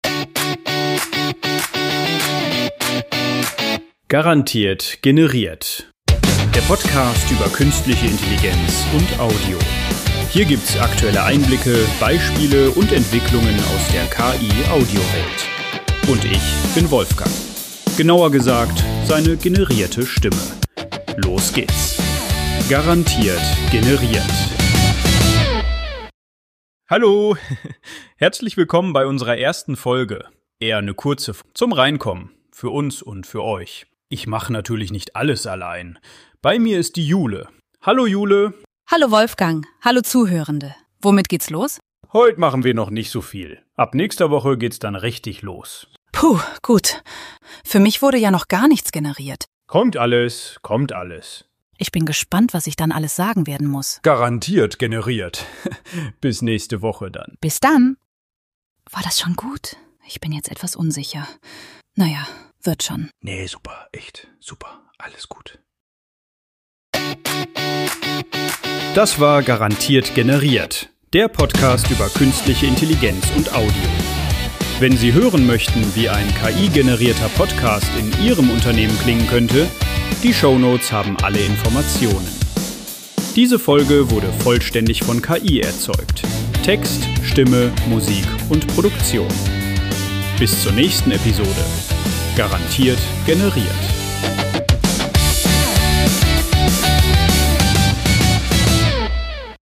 Bei "Garantiert generiert" sind alle Inhalte, Töne, Dialoge, Sounds
künstlich generiert.